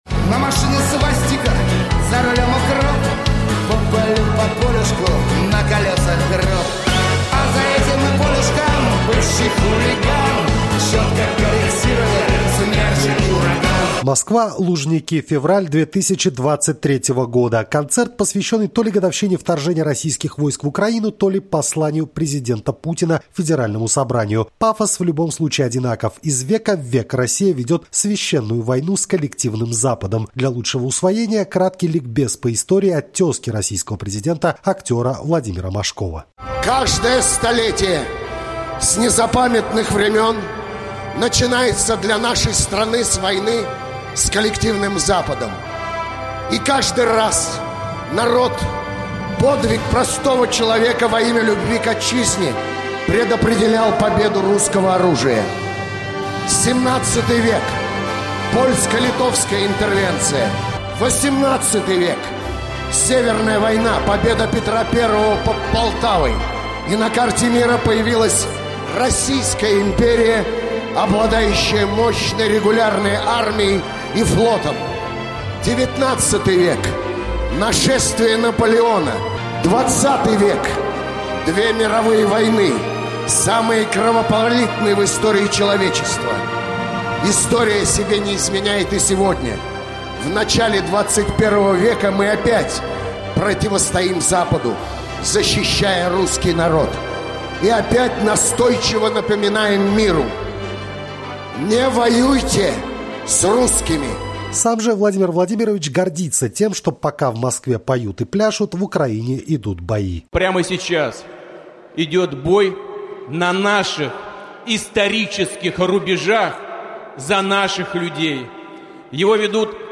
Троекратное «Ура!», хоть и не слишком уверенно исполненное, – очевидное продолжение заочного диалога между Путиным и американским лидером Джо Байденом.